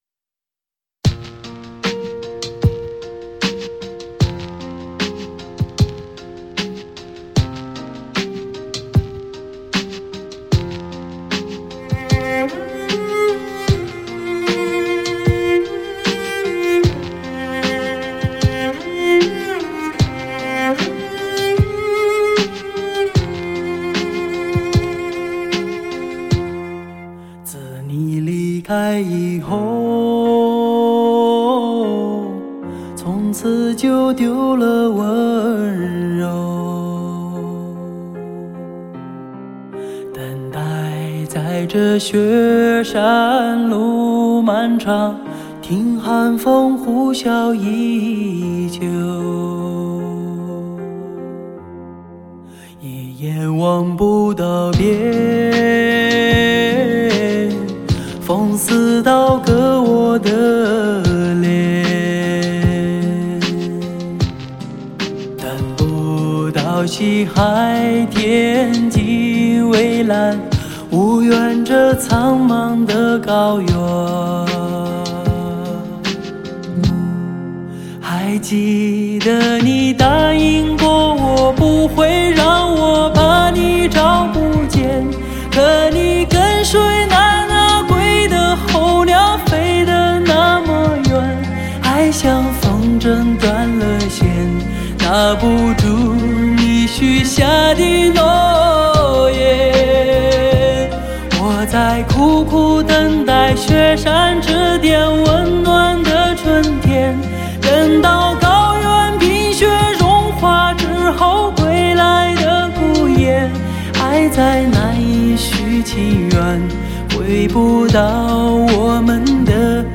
精选汽车专用无损音质
全方位多位环绕
发烧老情歌 纯音乐
极致发烧HI-FI人声测试碟